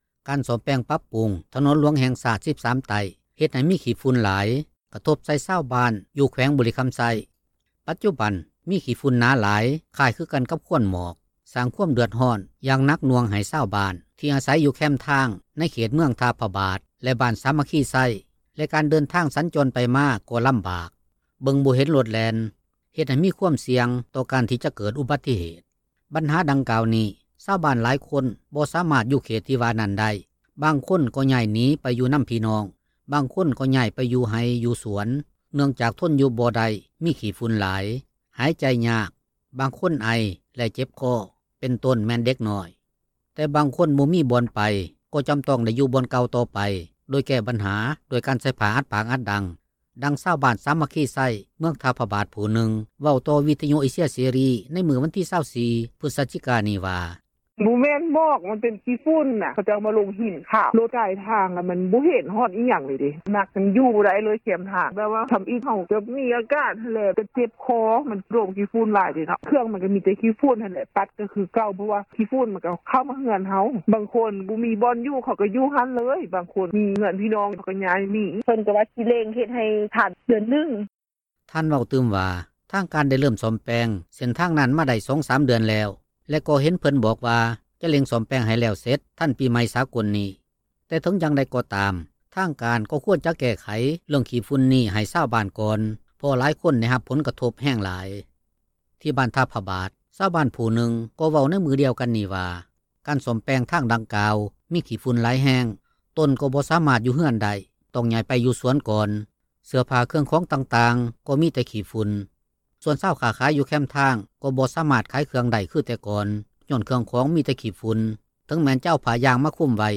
ດັ່ງຊາວບ້ານ ບ້ານສາມັກຄີໄຊ ເມືອງທ່າພະບາດ ຜູ້ນຶ່ງເວົ້າຕໍ່ວິທຍຸ ເອເຊັຽ ເສຣີ ໃນມື້ວັນທີ 24 ພຶສຈິການີ້ວ່າ: